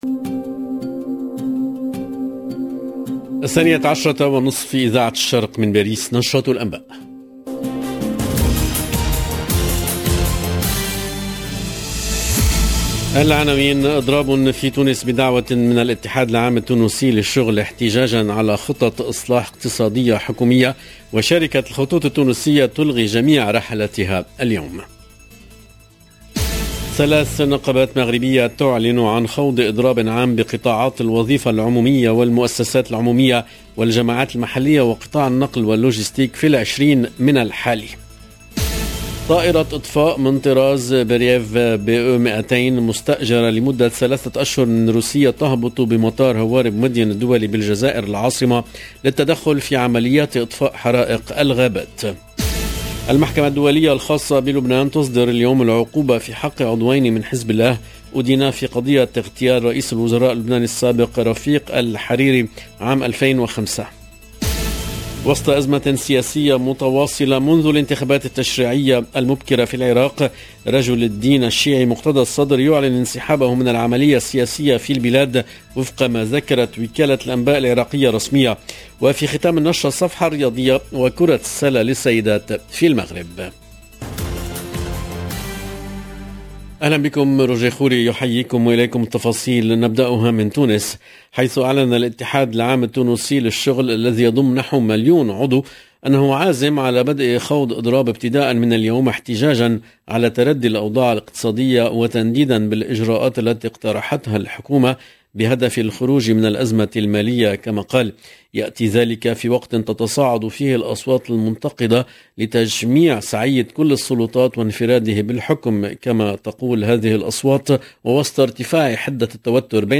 LE JOURNAL EN LANGUE ARABE DE LA MI-JOURNEE DU 16/06/22